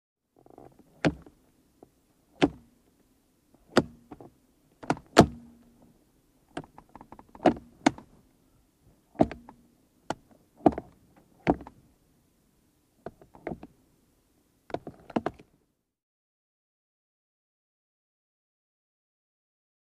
Floor Type Gear Shifter Movement; BMW.